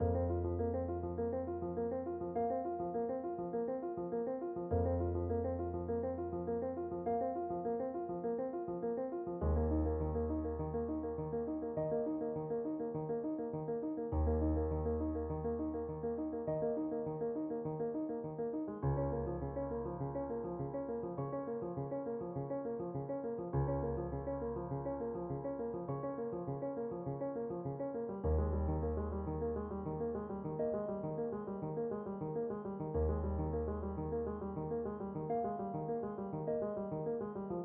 钢琴旋律153
Tag: 102 bpm Classical Loops Piano Loops 6.33 MB wav Key : Unknown